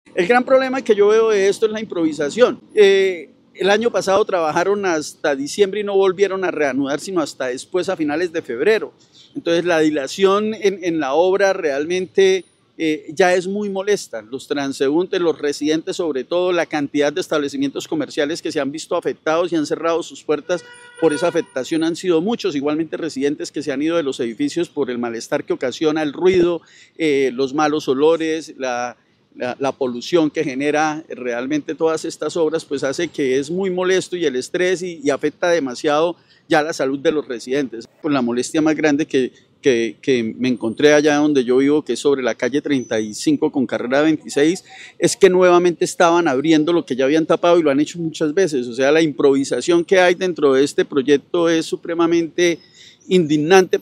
veedor ciudadano